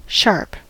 sharp: Wikimedia Commons US English Pronunciations
En-us-sharp.WAV